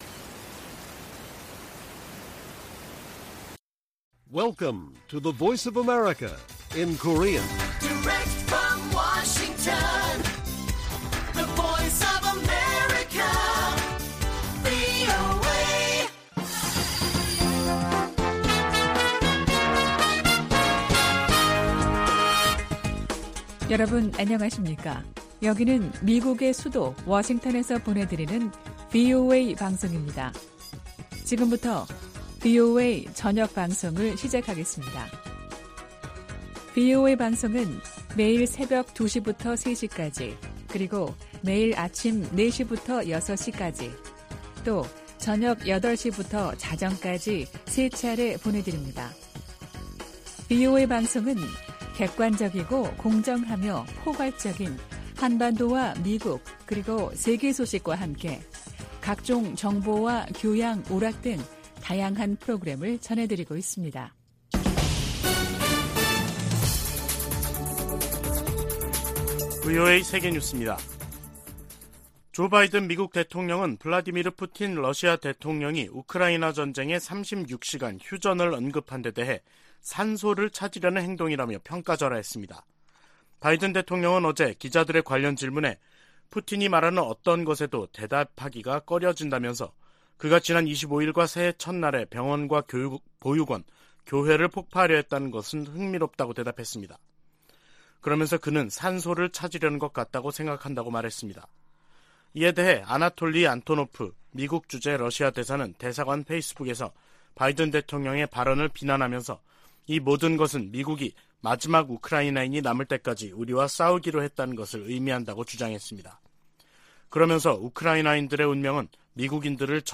VOA 한국어 간판 뉴스 프로그램 '뉴스 투데이', 2023년 1월 6일 1부 방송입니다. 미국과 일본이 워싱턴에서 외교 국방장관 회담을 개최한다고 미 국무부가 발표했습니다. 미 국방부가 북한의 핵탄두 보유량 증대 방침에 대한 우려를 표시했습니다.